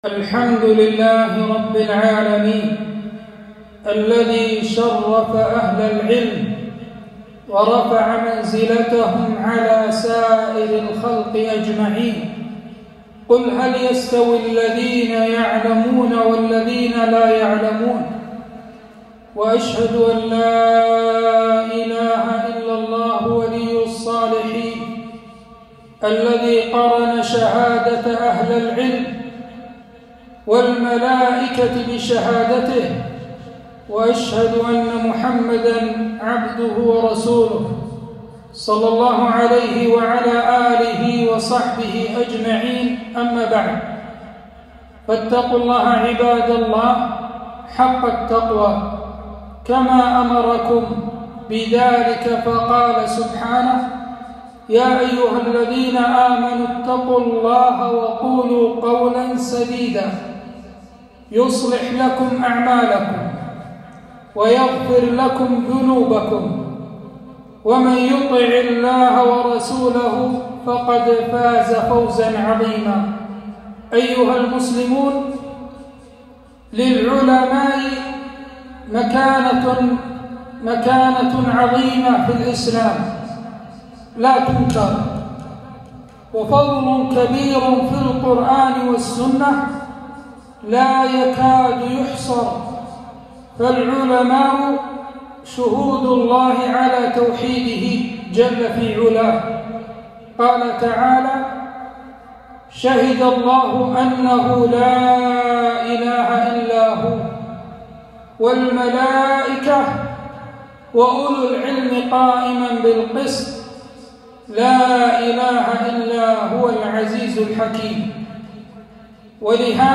خطبة - تعظيم قدر العلماء وخطورة تنقصهم